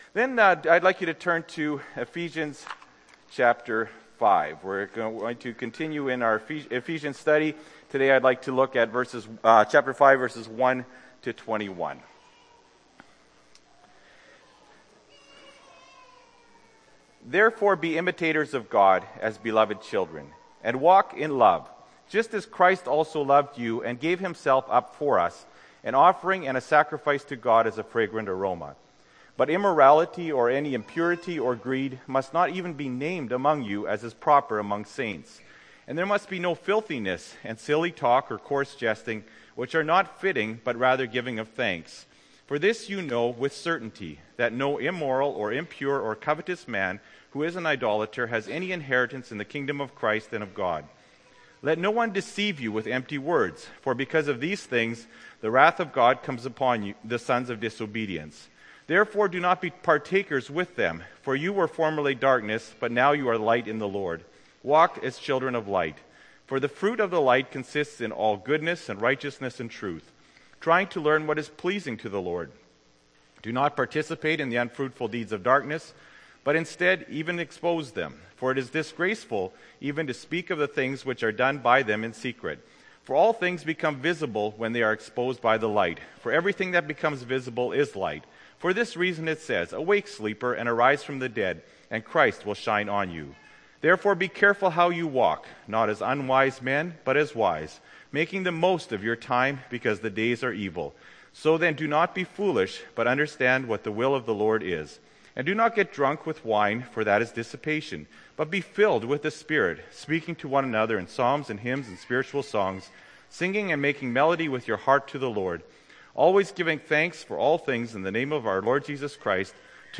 Apr. 7, 2013 – Sermon